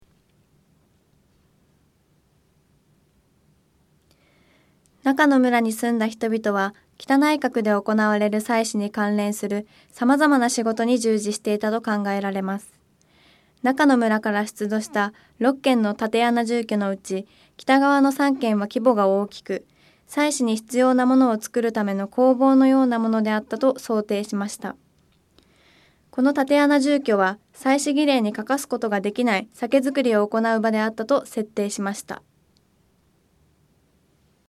この竪穴住居は祭祀儀礼に欠かすことができない酒造りを行なう場であったと設定しました。 音声ガイド 前のページ 次のページ ケータイガイドトップへ (C)YOSHINOGARI HISTORICAL PARK